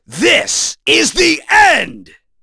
Chase-Vox_Skill4b.wav